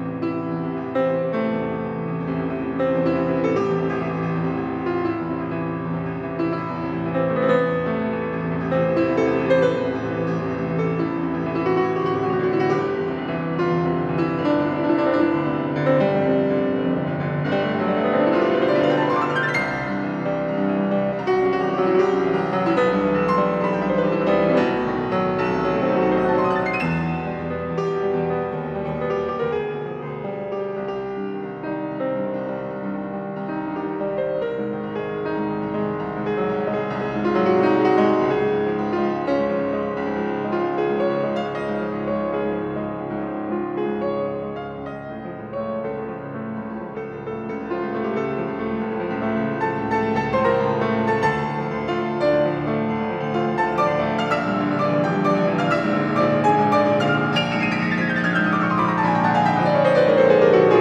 是鋼琴家20到30歲之間，不露鋒芒，從容而優雅。